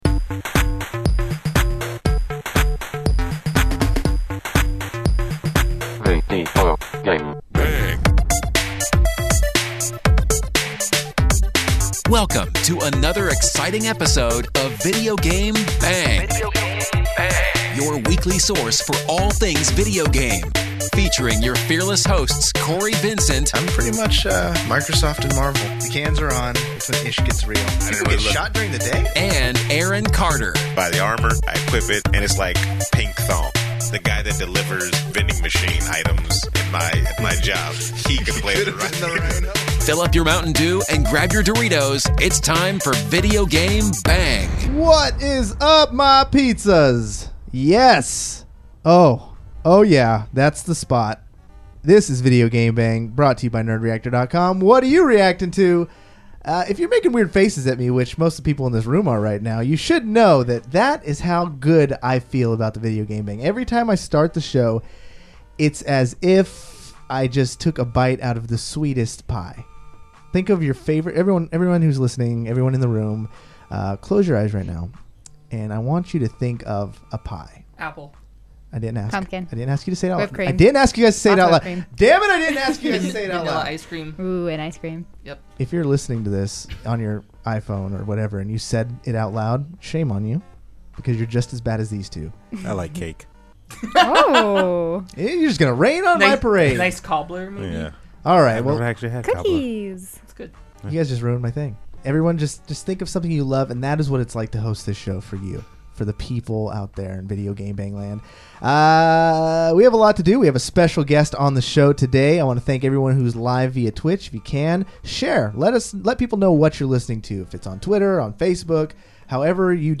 Typically our guests will just do the interview and try to escape